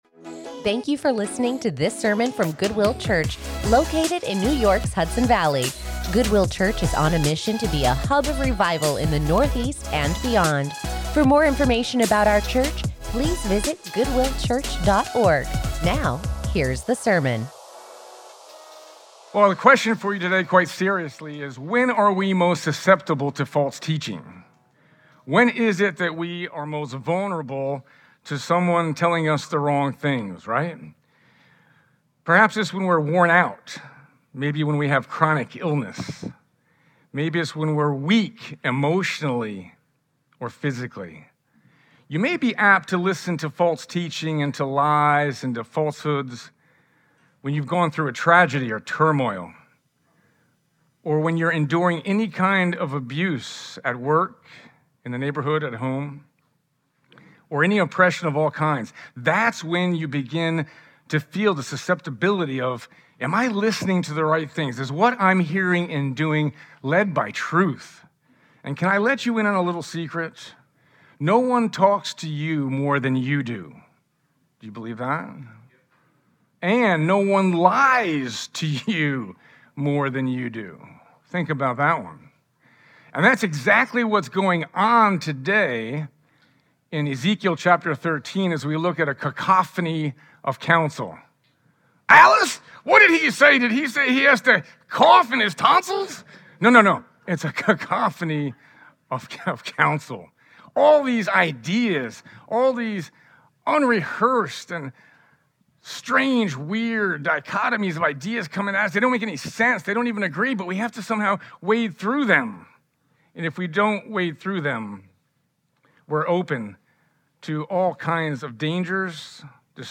Join us in studying God's Word as we take a break from our sermon series with this sermon "A Cacophony of Counsel” | Ezekiel